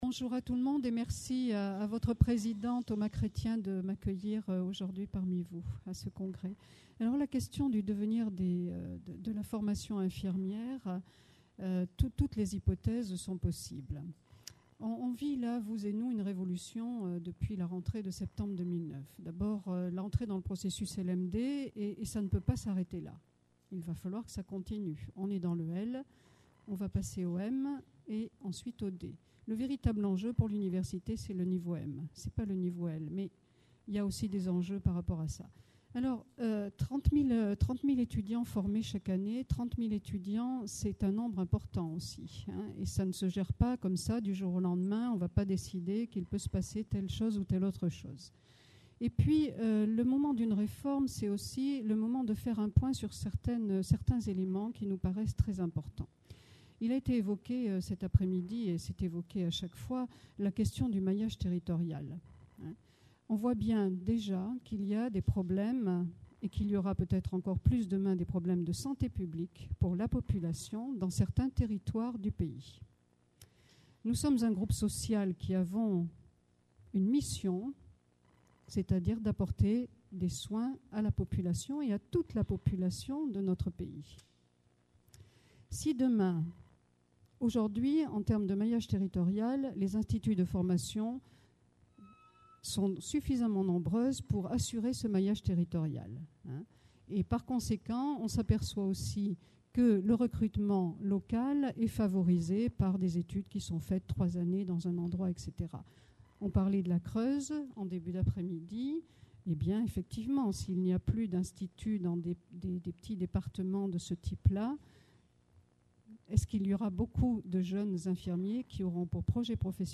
Conférence enregistrée lors du 10ème Congrès National des Etudiants en Soins Infirmiers (FNESI) – Paris le 26 novembre 2010 - L’évolution générale de l’offre de soins.